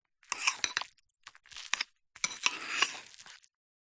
Шуршание ложки в перемешиваемой каше